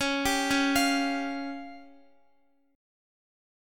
DbMb5 chord